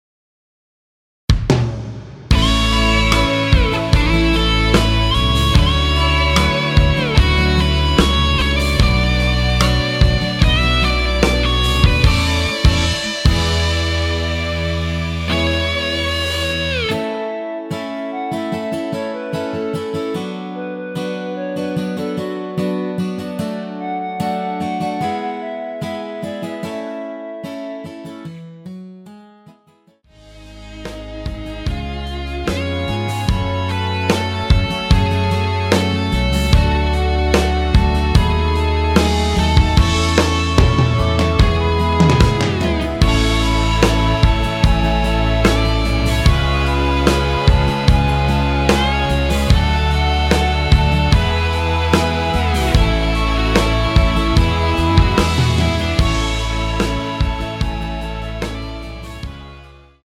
원키에서(+2)올린 멜로디 포함된 MR입니다.
노래방에서 노래를 부르실때 노래 부분에 가이드 멜로디가 따라 나와서
앞부분30초, 뒷부분30초씩 편집해서 올려 드리고 있습니다.